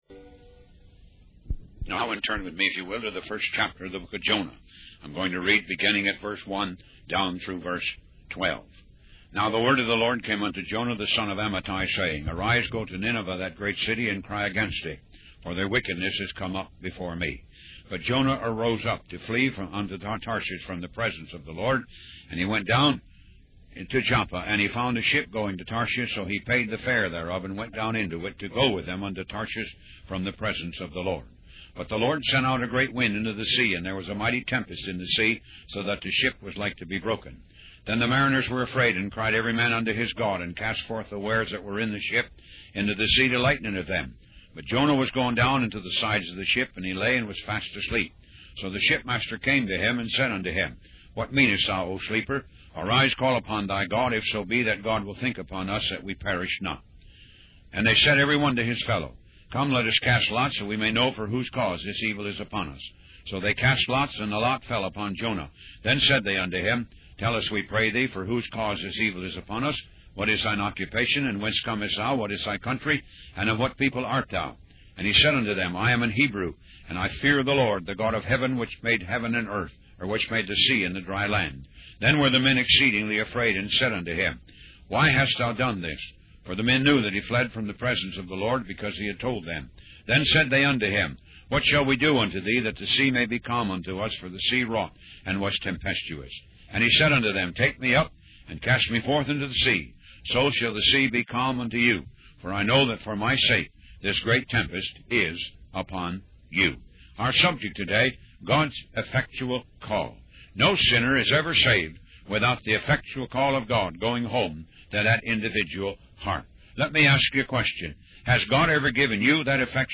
Talk Show Episode, Audio Podcast, Moga - Mercies of God Association and Jonah, The Nation of Israel on , show guests , about Jonah,The Nation of Israel, categorized as History,Christianity,Society and Culture